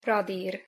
Pronunciation Hu Radír (audio/mpeg)